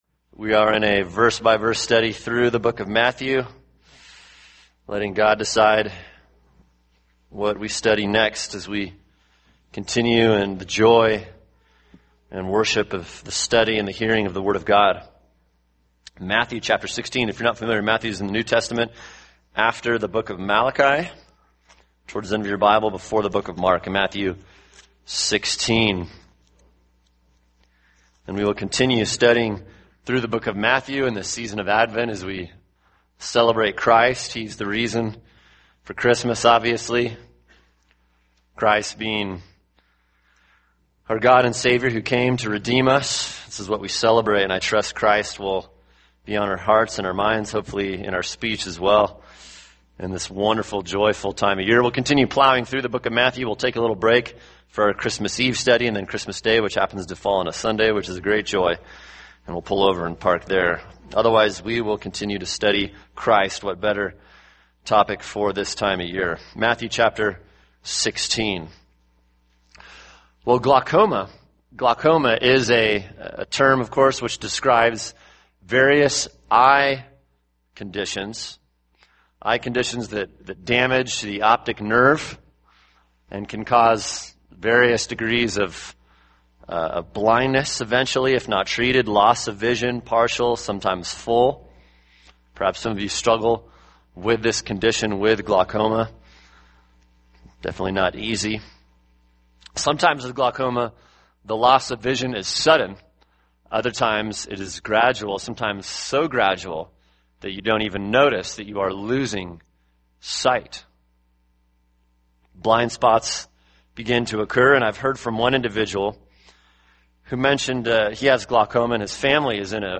[sermon] Matthew 16:1-12 – Spiritual Blindness | Cornerstone Church - Jackson Hole